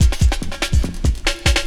16 LOOP02 -L.wav